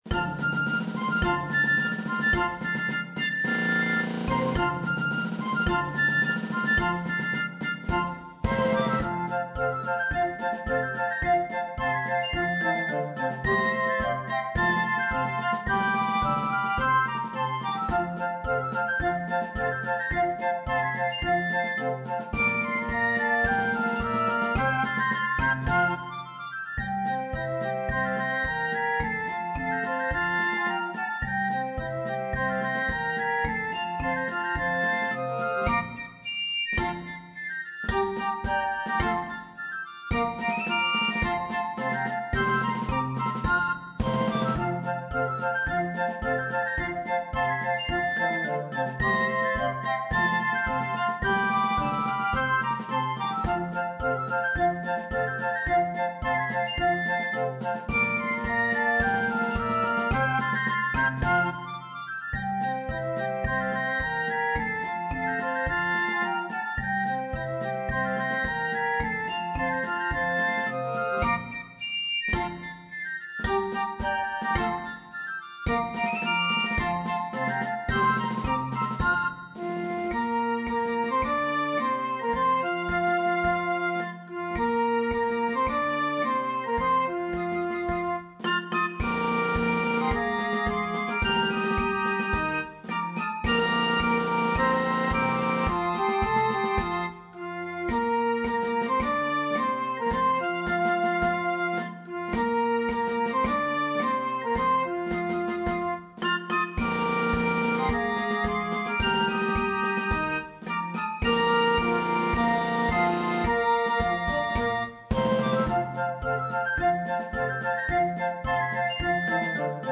Zurück zu Traditionelles & Märsche
Dieses Arrangement klingt vor allem auf der Straße gut!